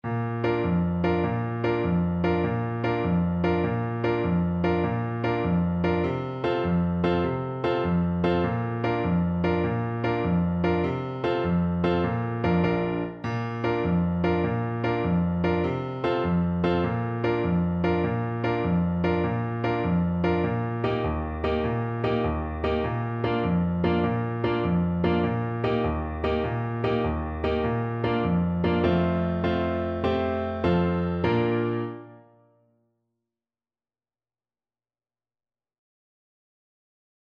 6/8 (View more 6/8 Music)
Allegro .=c.100 (View more music marked Allegro)